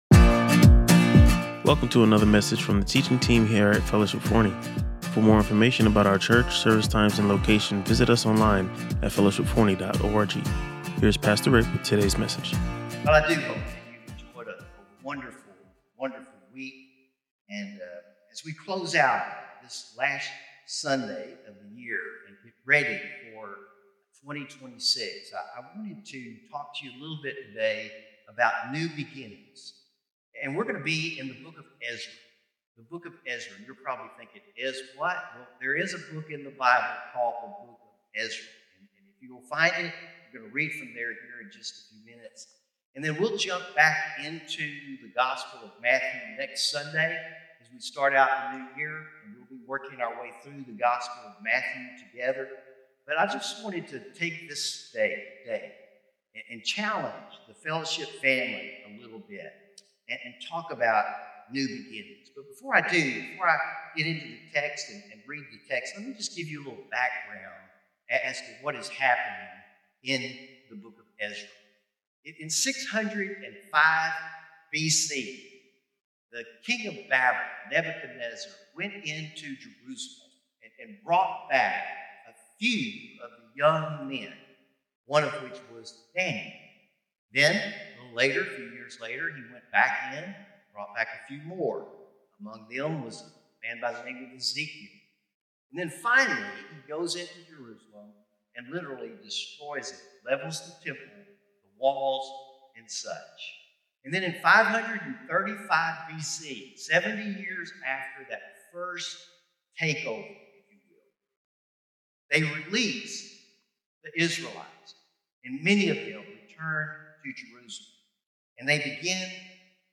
Listen to or watch the full sermon and discover how you can be part of Fellowship’s exciting next chapter.